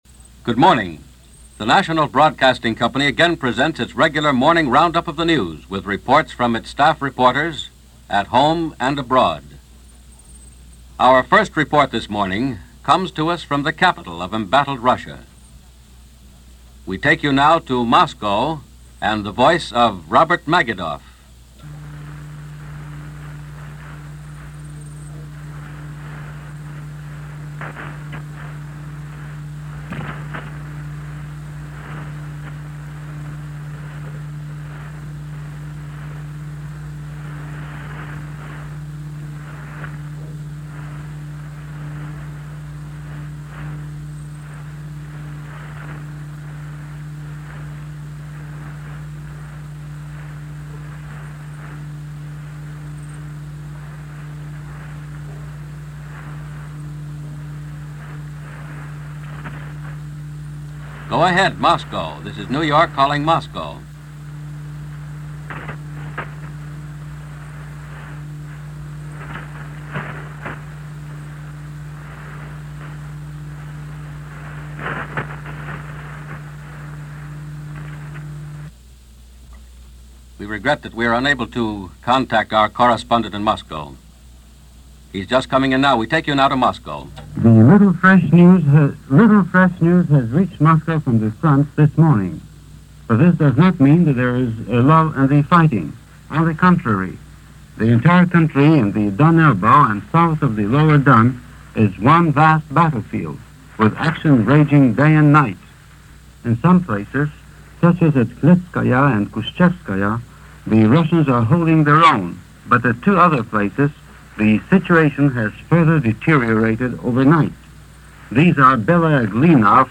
The Eastern Front - Talk Of A Second Front - The Indian Question - August 7, 1942 - News from NBC Radio World News Roundup.